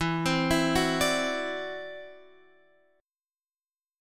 E7sus2#5 chord